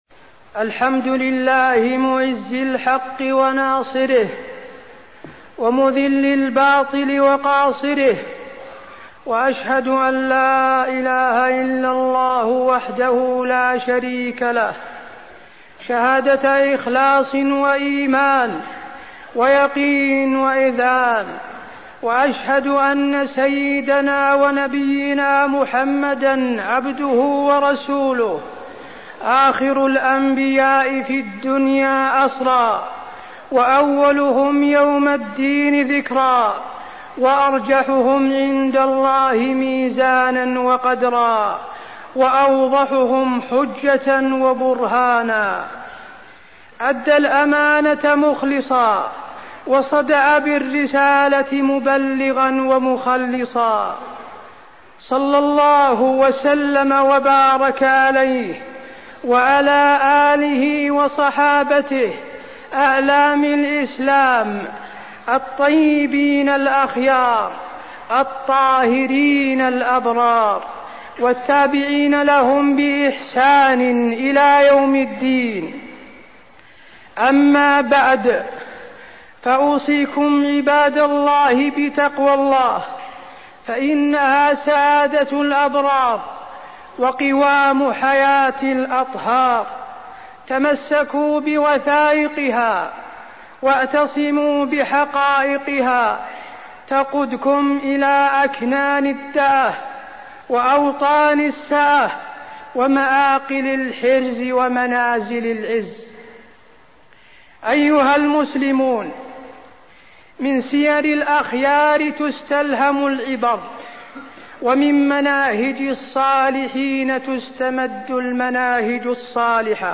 تاريخ النشر ١٥ ذو القعدة ١٤١٨ هـ المكان: المسجد النبوي الشيخ: فضيلة الشيخ د. حسين بن عبدالعزيز آل الشيخ فضيلة الشيخ د. حسين بن عبدالعزيز آل الشيخ مقتطفات من سيرة الرسول صلى الله عليه وسلم